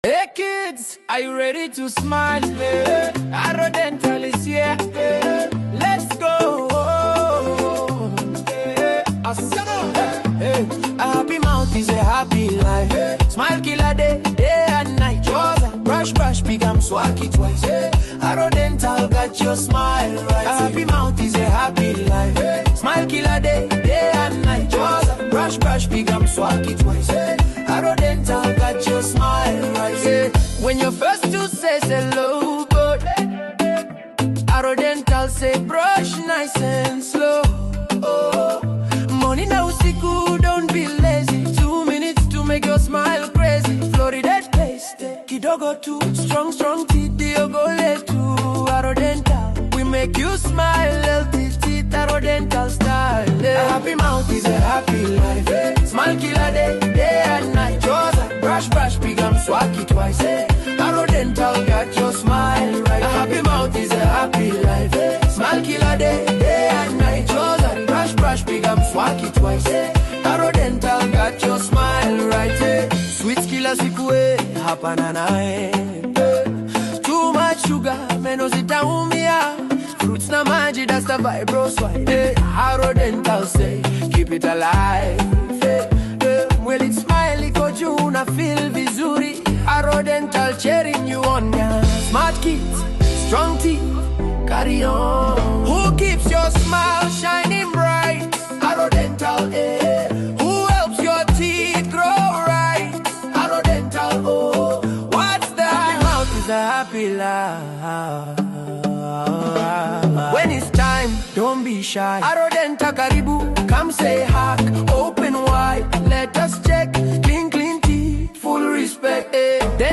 Arrow Dental Kids Anthem – A Happy Mouth Is a Happy Life  WOHD 2026 Kenya - Arrow Dental Centre Kenya🇰🇪_1.mp3